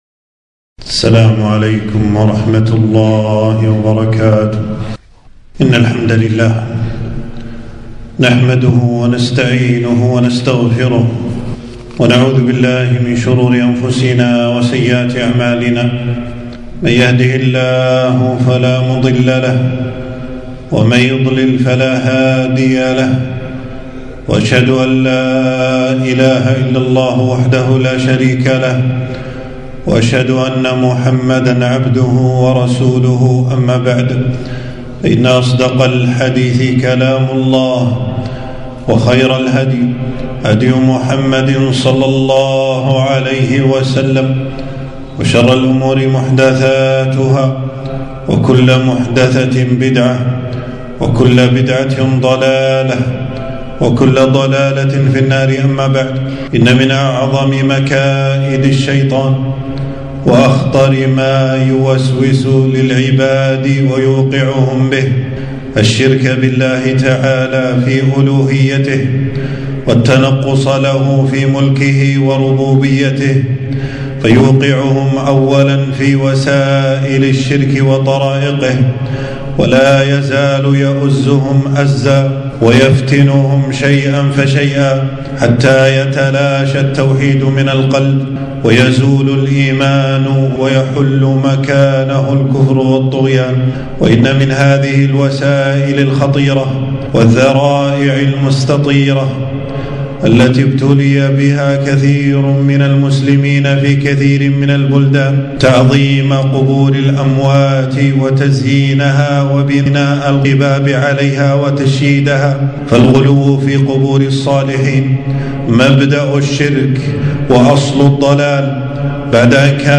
خطبة - من آداب المقابر